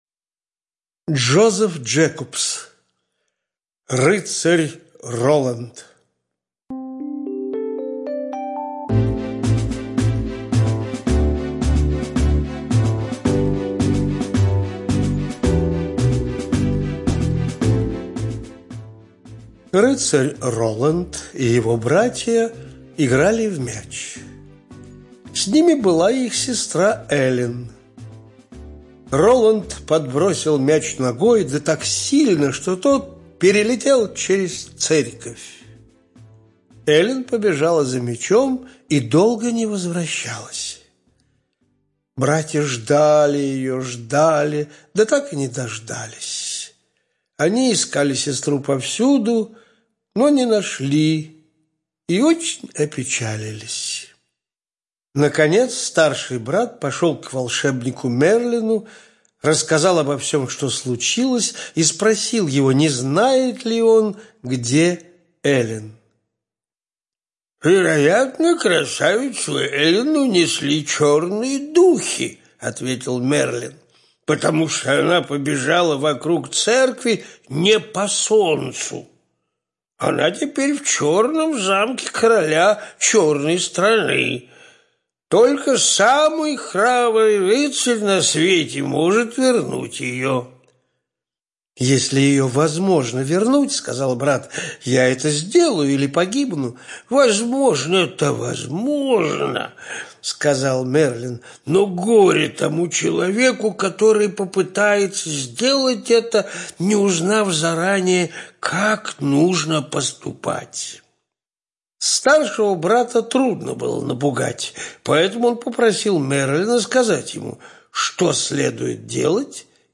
Рыцарь Роланд - аудиосказка Джекобса - слушать онлайн